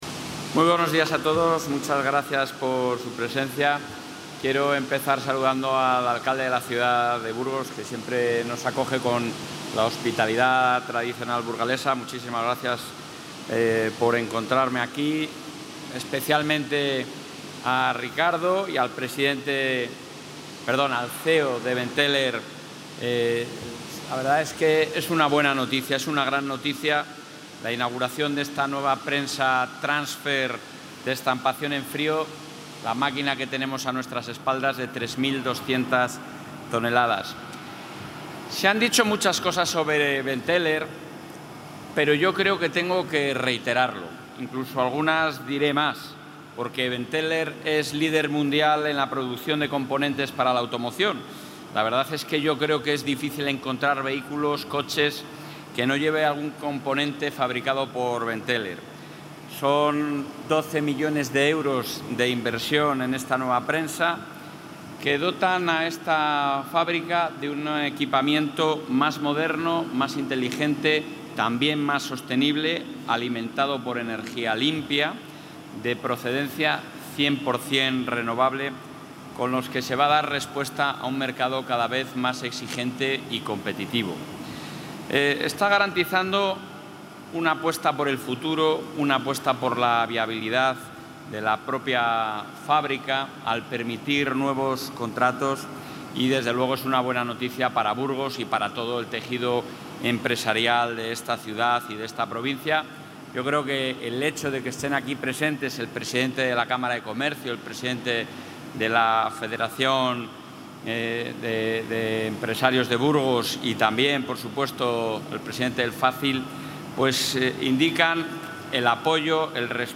Intervención del presidente de la Junta